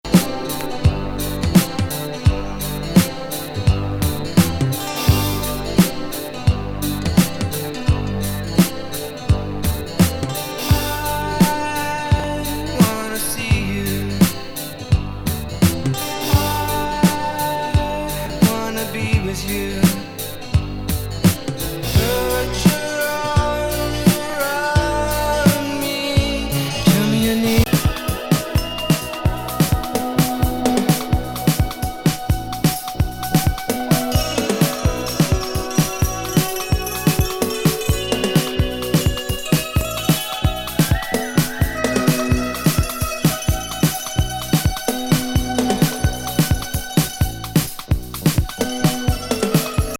アダルト・メロウなホッコリ
エスノ・トランス感覚がいい塩梅のインスト